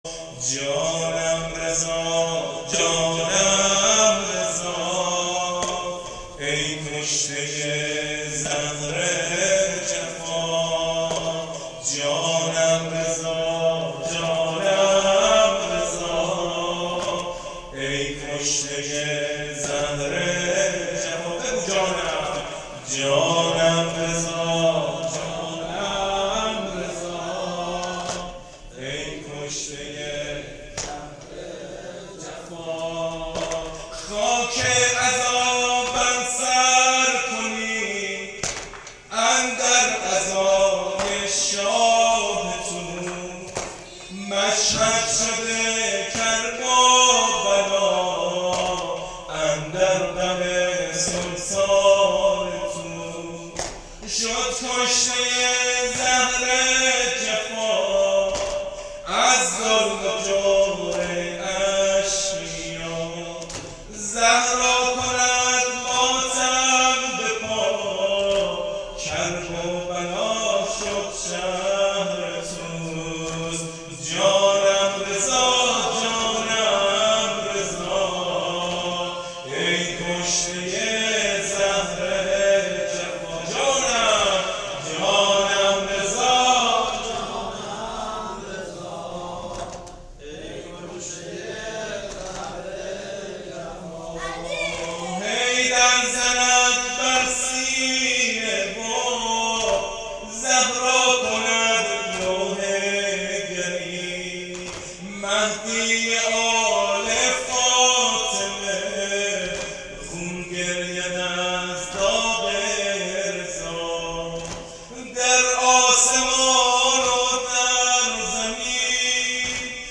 نوحه جانم رضا جانم رضا